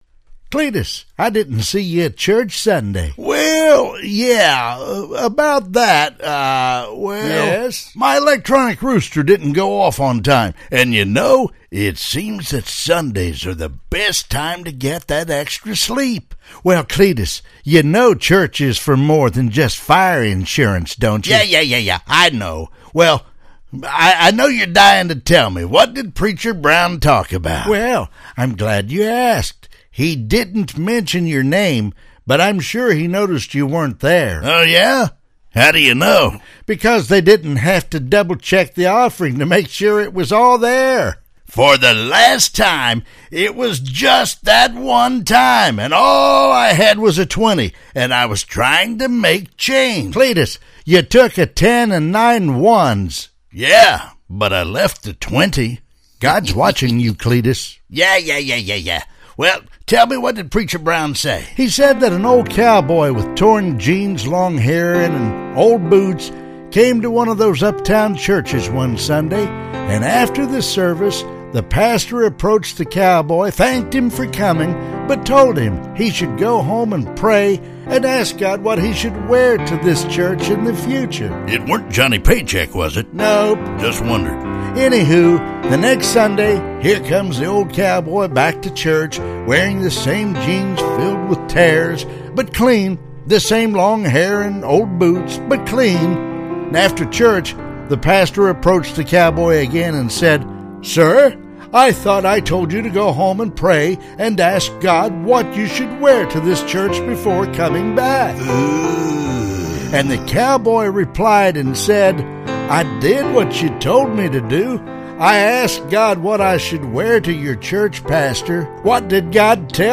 Male
My voice ranges from deep Bass to Baritone.
Character / Cartoon
Words that describe my voice are Deep, Southern, Cowboy.
0504Old_Cowboy_At_Church.mp3